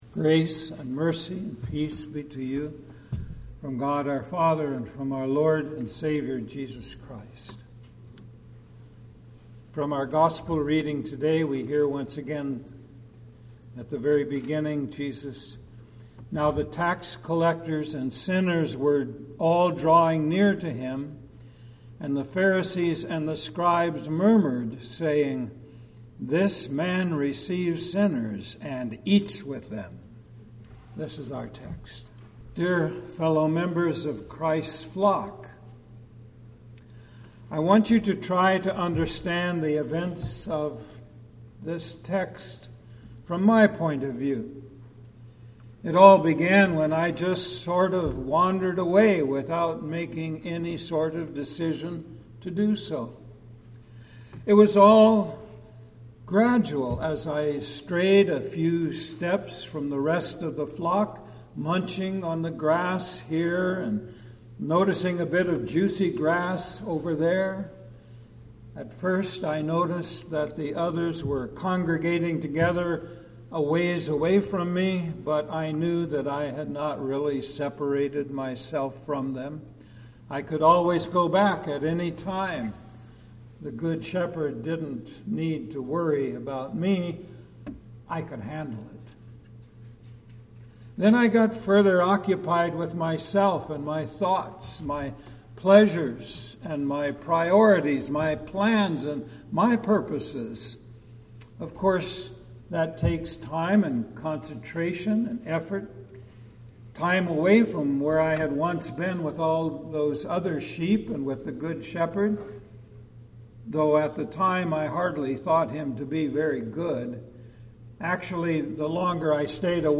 The 14th Sunday after Pentecost Preacher